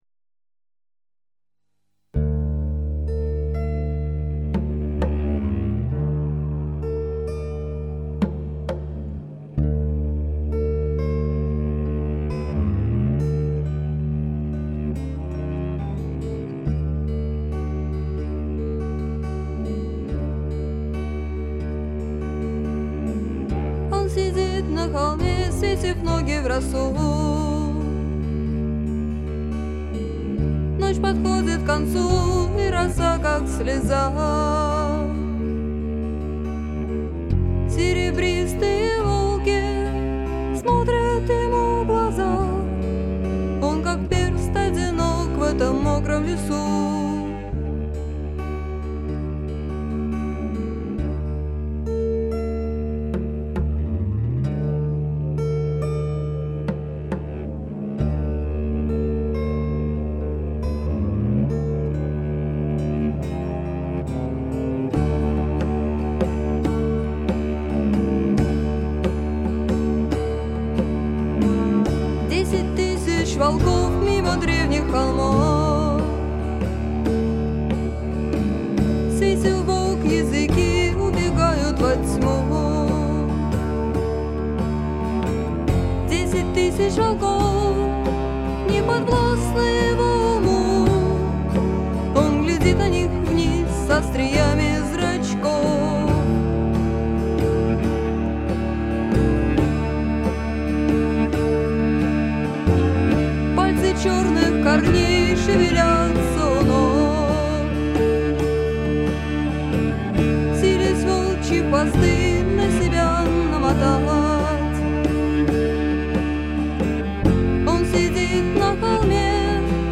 вокалы